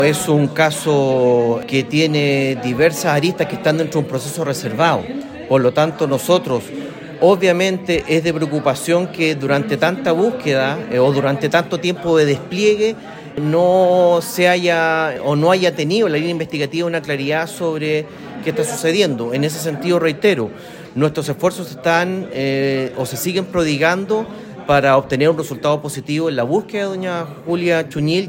Ante ello, el delegado presidencial subrogante, Alejandro Reyes, al ser consultado por la falta de resultados pese a todo el esfuerzo y despliegue gestionado por el Gobierno, reconoció su preocupación apuntando a que las líneas investigativas no están teniendo la claridad de lo que está sucediendo.